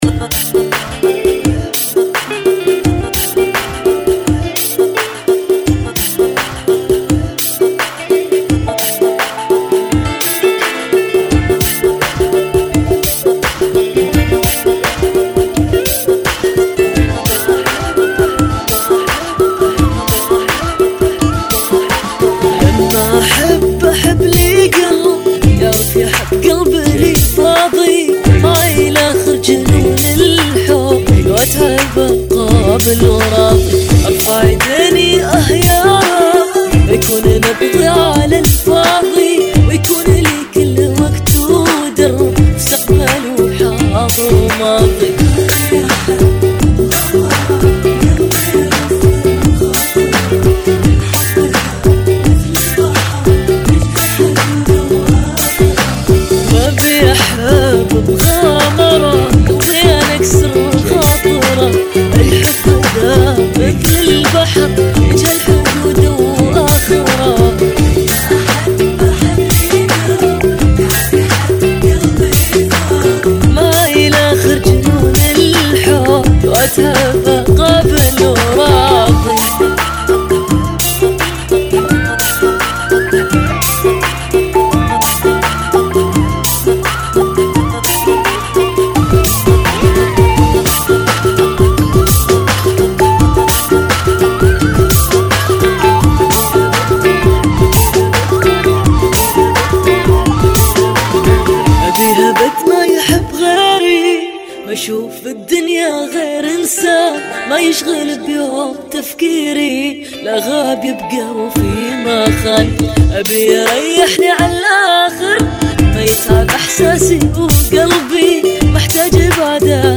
[ 85 Bpm ]